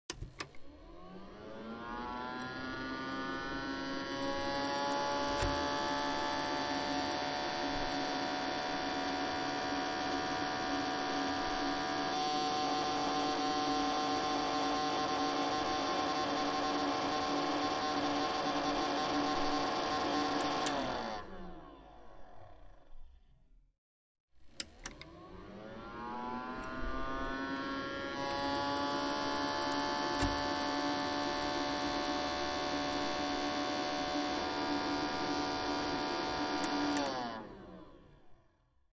Звук вращения
CD диск вращается в дисководе:
cd-disk-vraschaetsja-v-diskovode.mp3